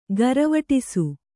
♪ garavaṭṭisu